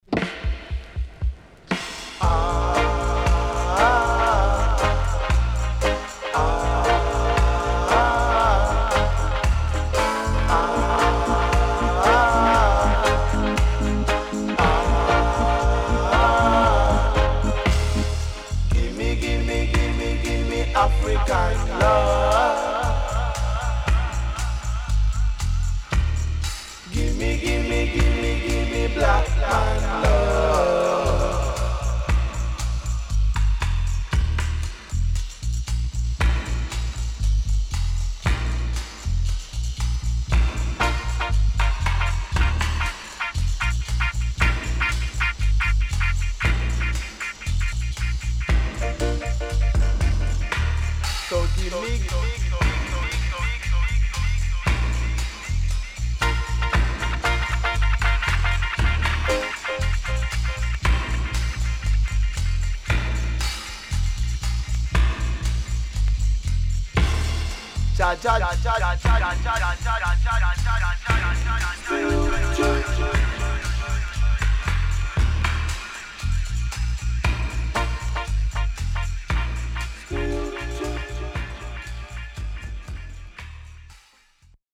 HOME > Back Order [VINTAGE 7inch]  >  KILLER & DEEP